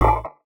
UIClick_Metal Hits Muffled 01.wav